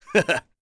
Chase-Vox-Laugh.wav